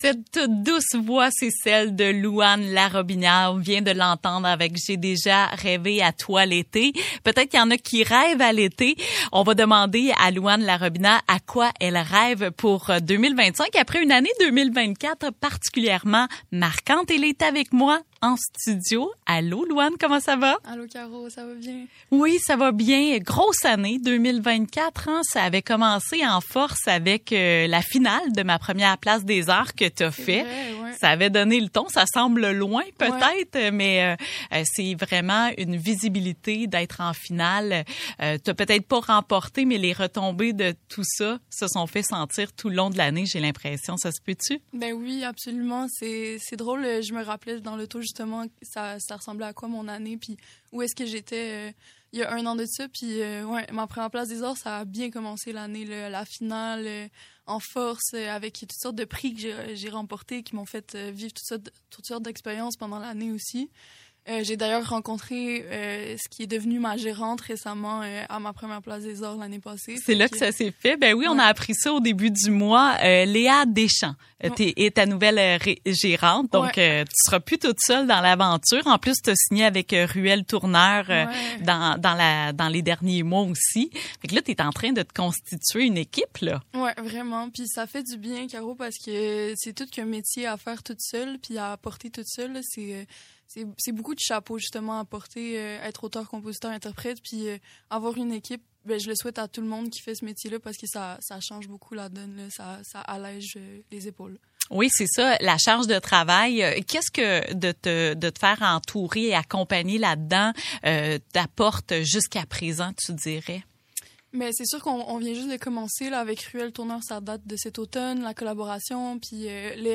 série d’entrevue